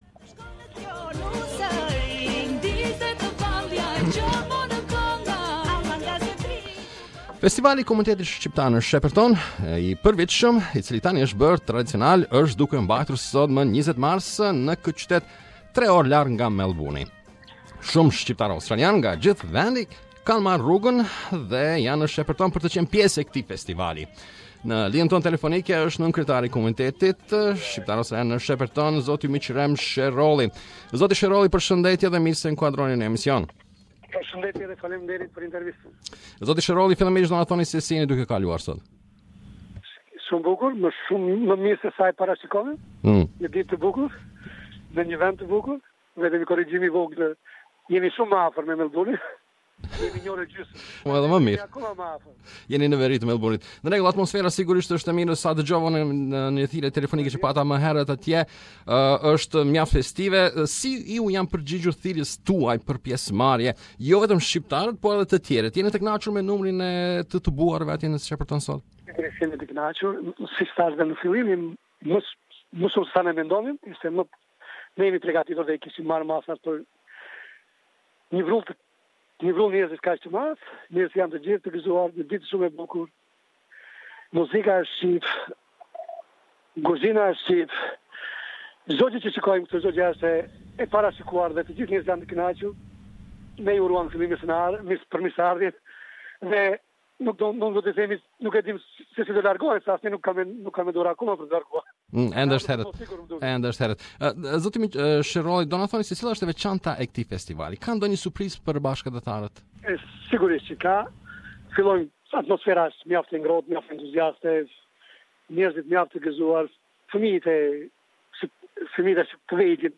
The Albanian Festival in Shepparton
Festivali I 17-te i Komunitetit Shqiptar ne Shepparton u mbajt me 20 mars.